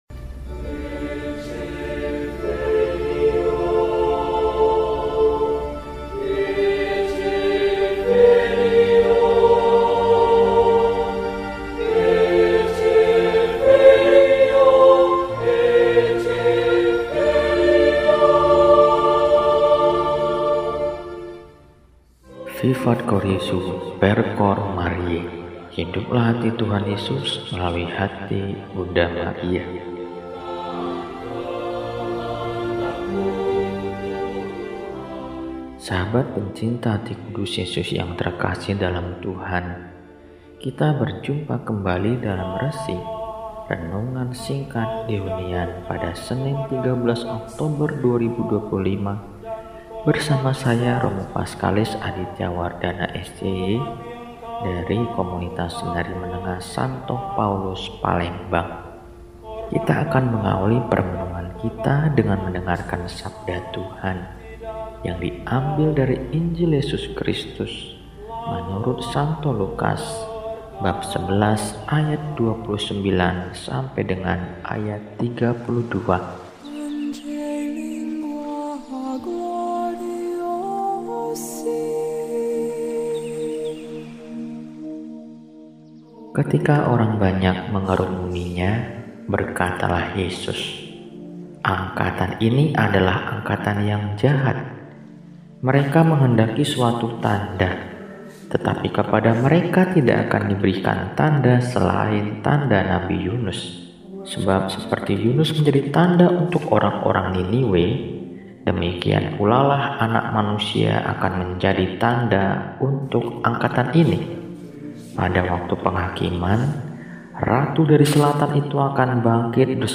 Senin, 13 Oktober 2025 – Hari Biasa Pekan XXVIII – RESI (Renungan Singkat) DEHONIAN